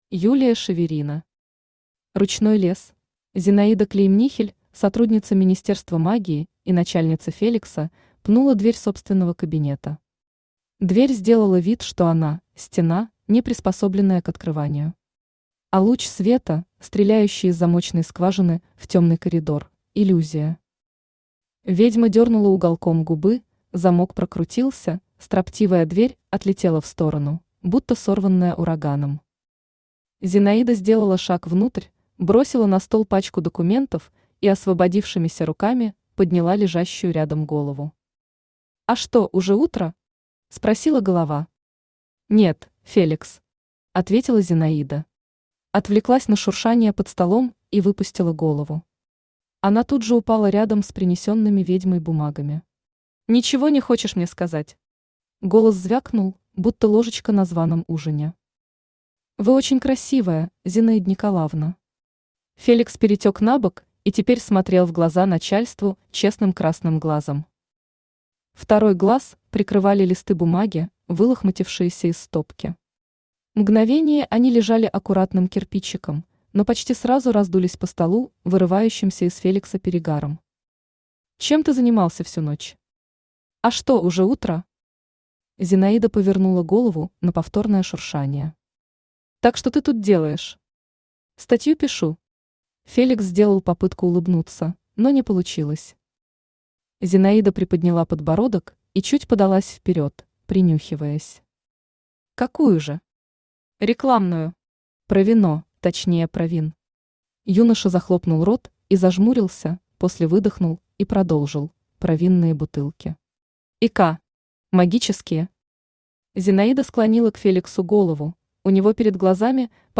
Аудиокнига Ручной лес | Библиотека аудиокниг
Aудиокнига Ручной лес Автор Юлия Шеверина Читает аудиокнигу Авточтец ЛитРес.